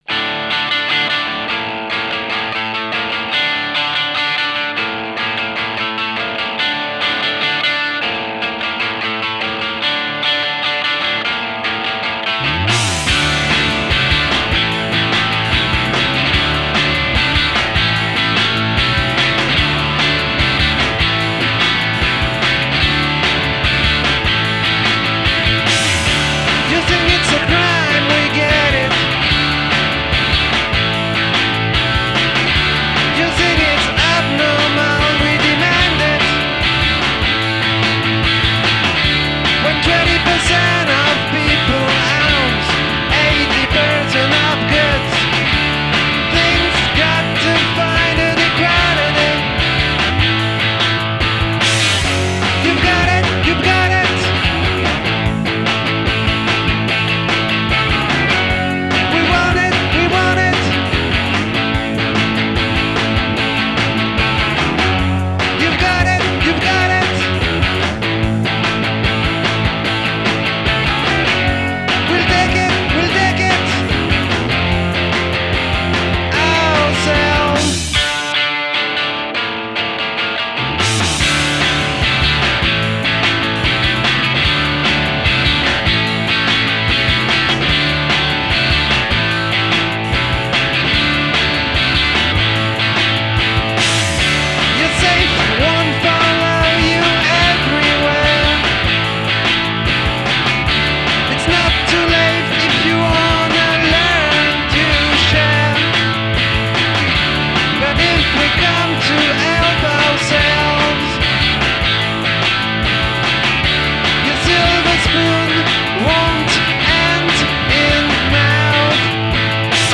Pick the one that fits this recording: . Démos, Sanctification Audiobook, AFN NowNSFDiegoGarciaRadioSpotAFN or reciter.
. Démos